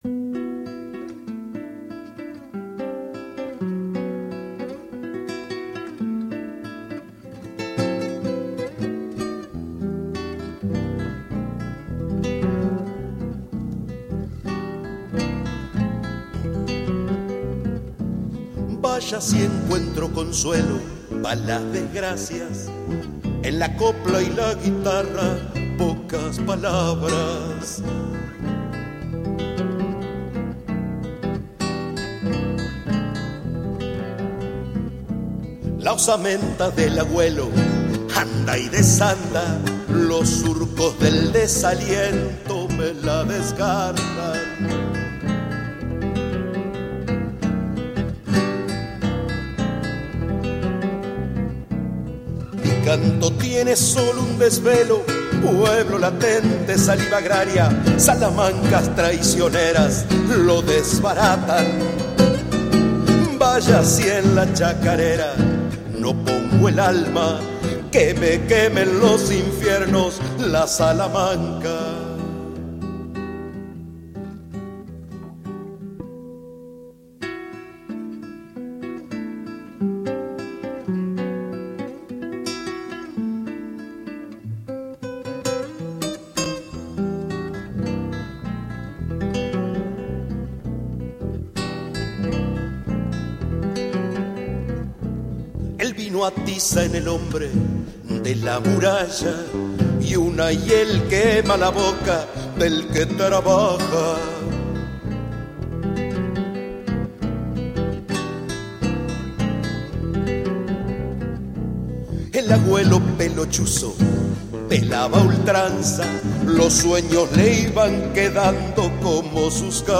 Chacarera